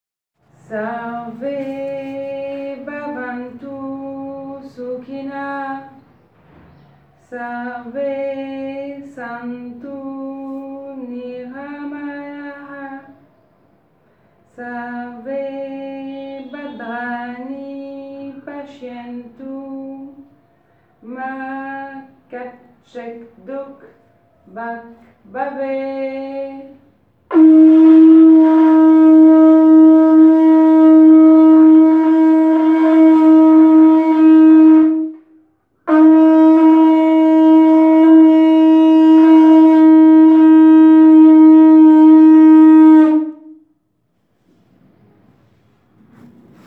An audio recording of her chanting is available below.